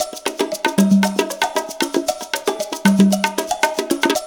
CONGA BEAT30.wav